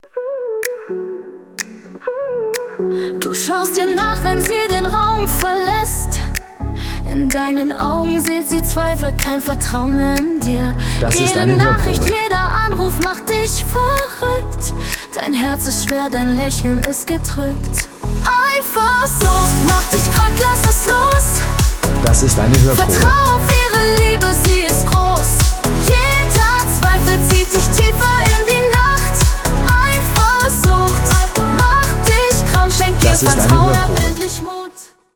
ist ein emotionaler, aber auch ermutigender Song